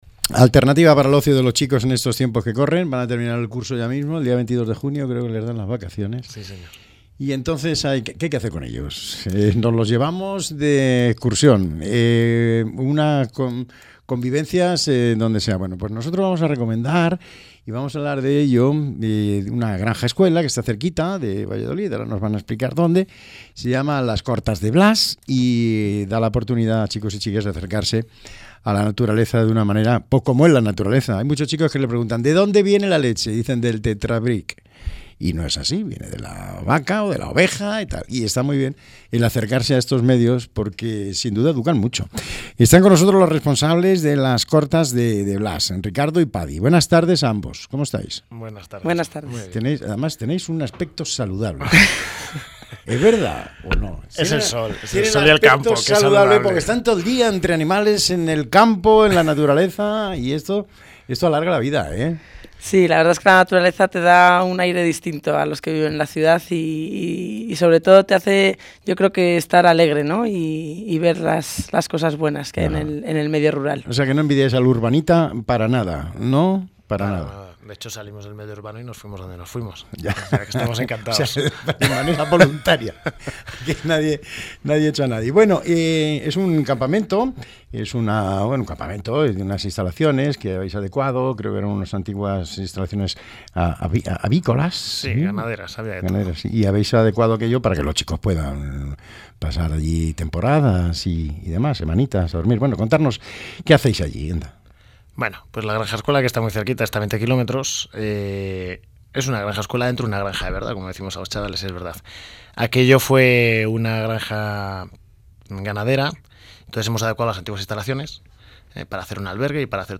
entrevista.mp3